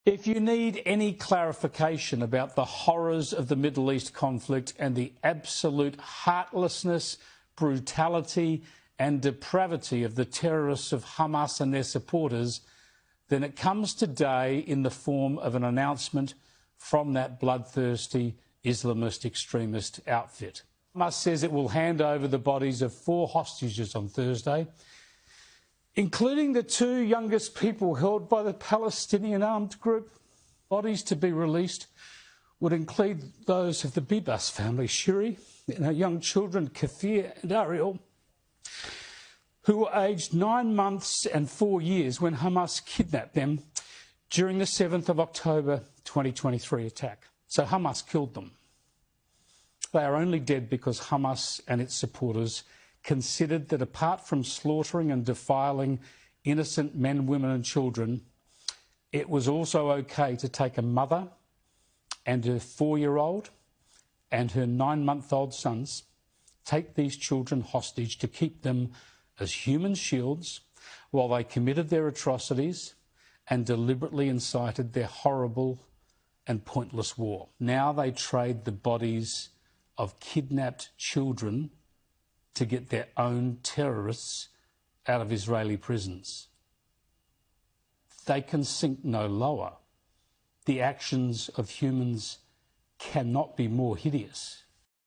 Australian presenter cries bitterly for the members of the Biebs family